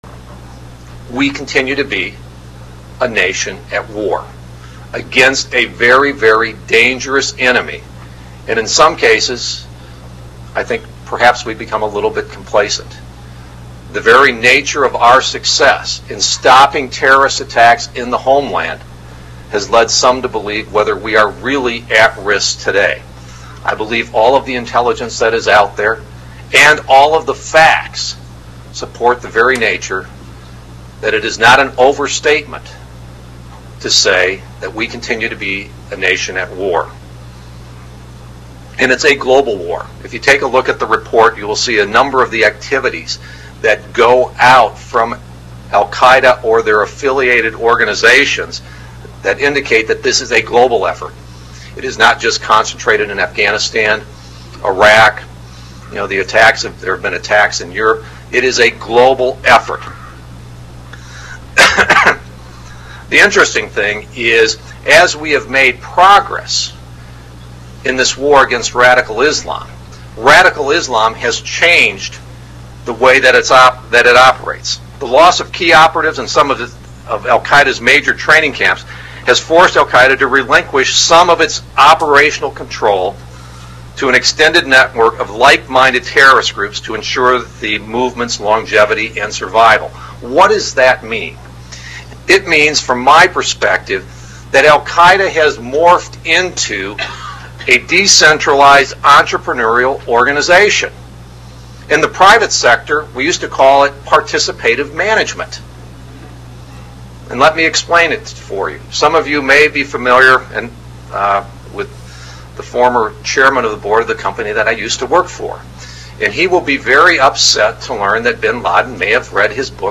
Recently, Representative Peter Hoekstra (R-MI) who is the chairman of the House Intelligence Committee gave a speech1 at the American Enterprise Institute.